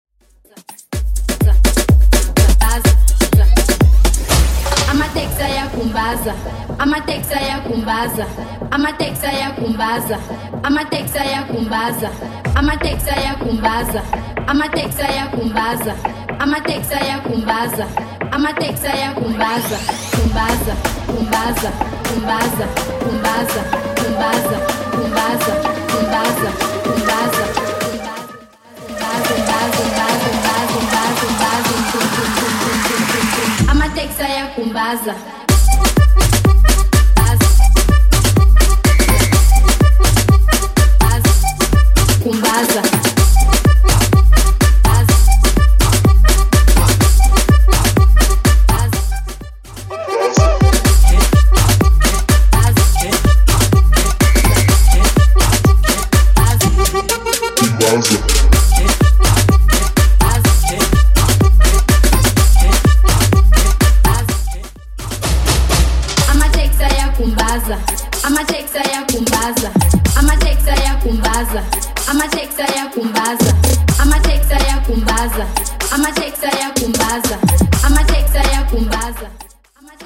Genre: TOP40 Version: Clean BPM: 93